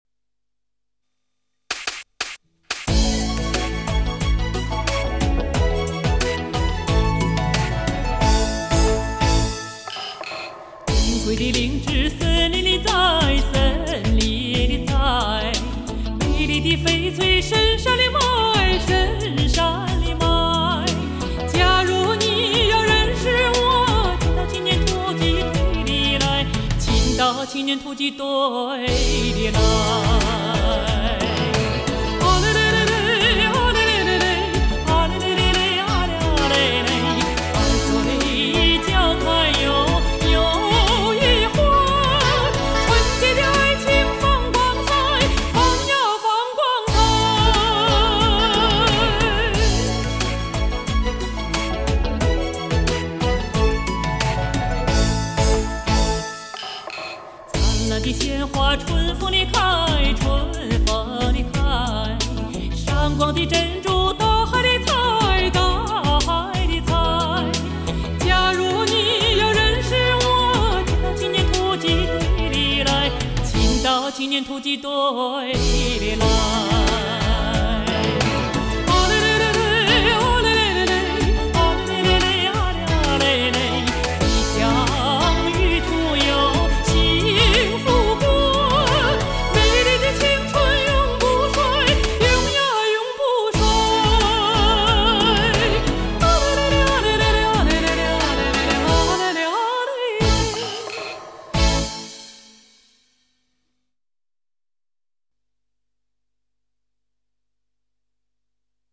人们会自然地的想起一种声音，宛如天籁之音，是那么纯真 亲切 悠扬 浓情 壮美，